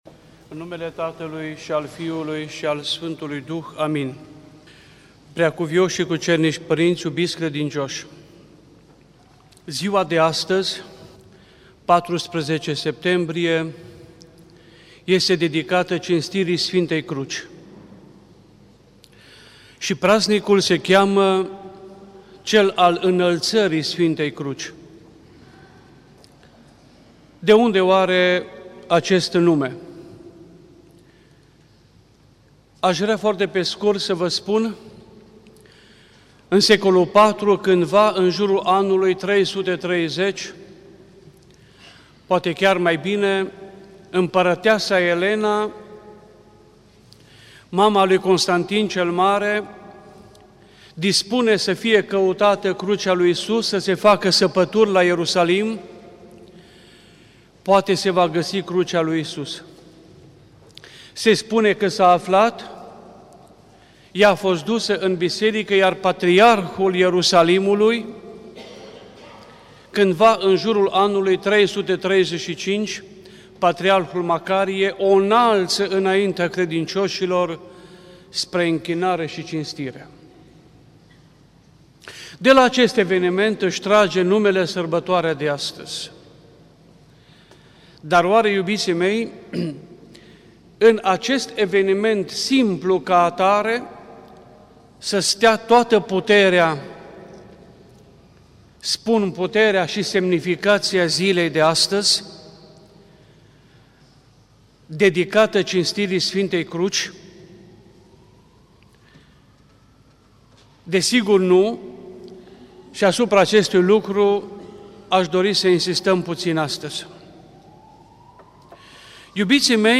Cuvinte de învățătură Predică la sărbătoarea Înălțării Sfintei Cruci| Pr.